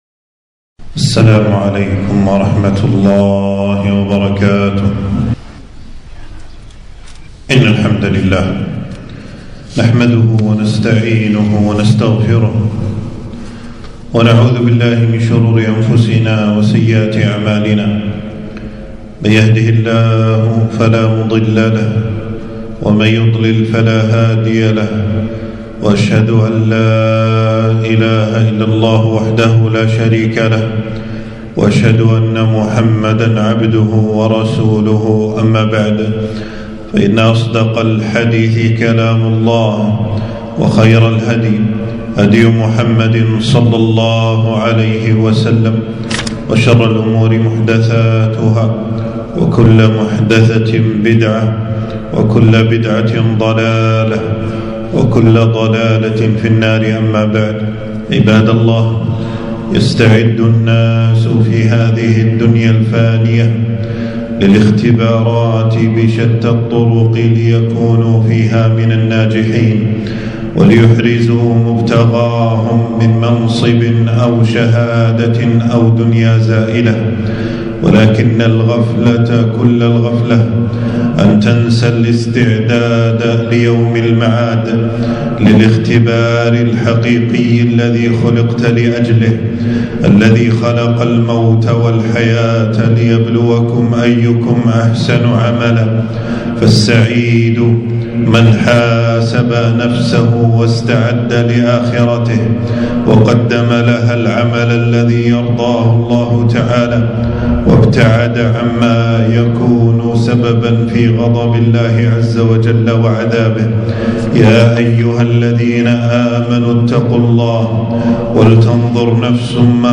خطبة - الاستعداد ليوم المعاد